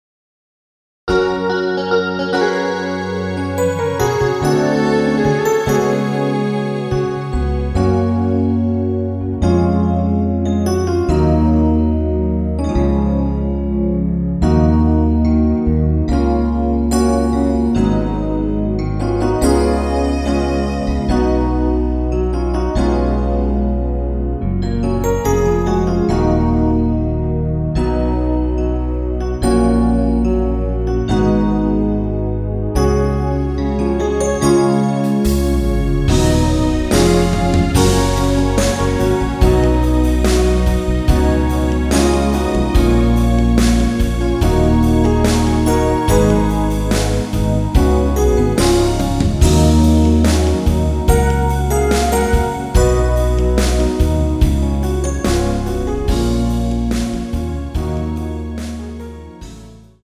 페이드 아웃곡이라 라이브하기좋게 엔딩을 만들어 놓았습니다.
F#
앞부분30초, 뒷부분30초씩 편집해서 올려 드리고 있습니다.
중간에 음이 끈어지고 다시 나오는 이유는